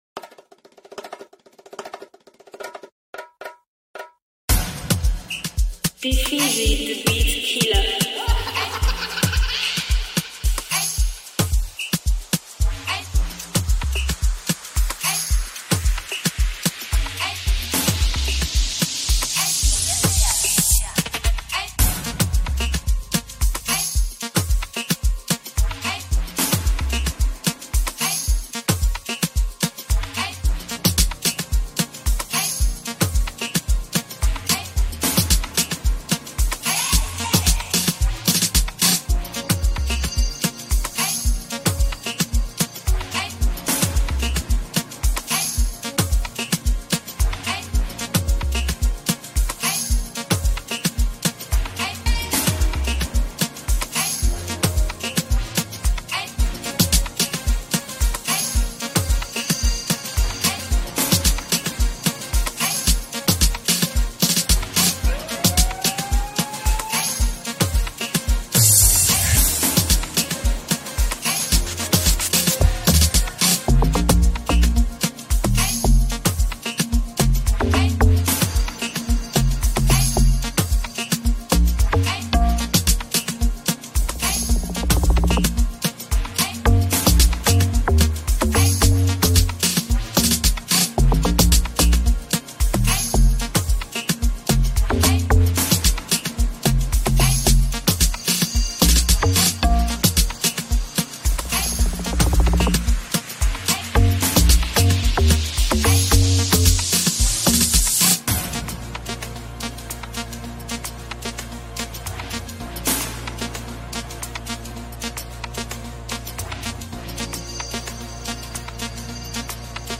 2021-08-16 1 Instrumentals 0
instrumental Free beat